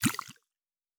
Potion and Alchemy 02.wav